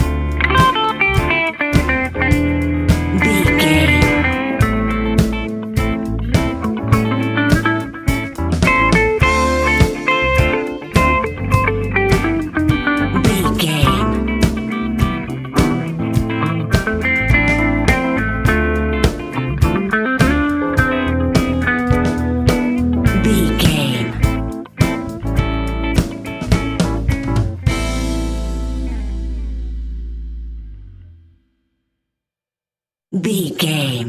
Ionian/Major
A♭
house
electro dance
synths
techno
trance
instrumentals